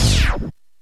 RAPSYNHIT2.wav